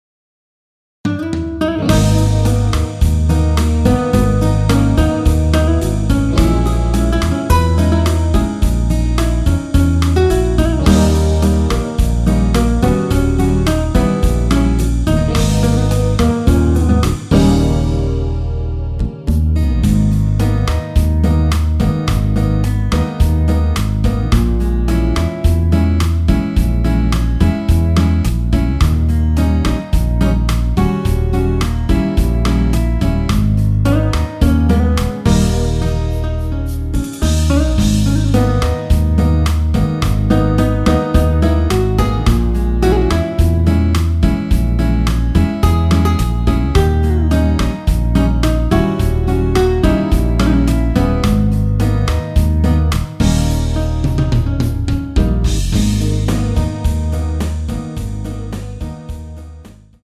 G#m
◈ 곡명 옆 (-1)은 반음 내림, (+1)은 반음 올림 입니다.
앞부분30초, 뒷부분30초씩 편집해서 올려 드리고 있습니다.